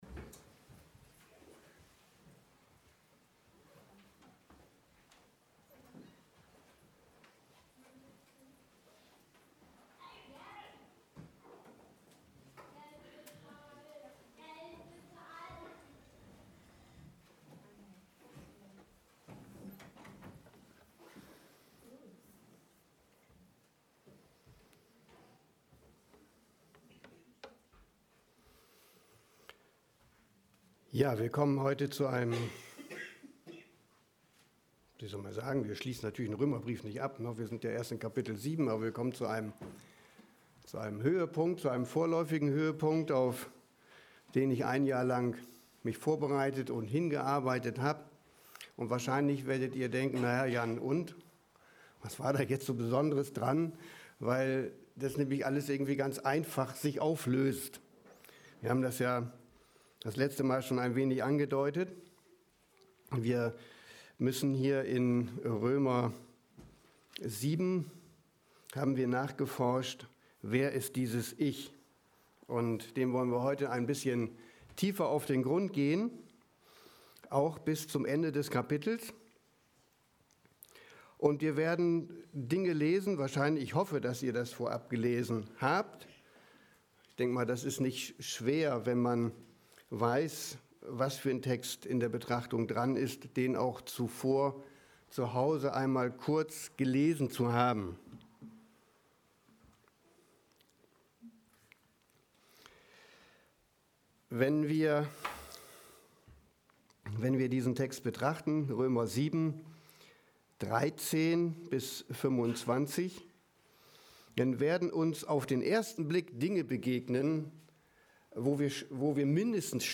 Predigt 06.12.2020